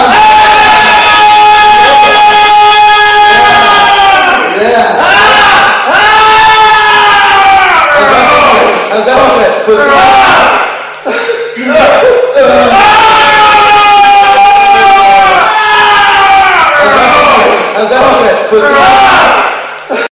very loud gachi aaaahhhh Meme Sound Effect
This sound is perfect for adding humor, surprise, or dramatic timing to your content.
very loud gachi aaaahhhh.mp3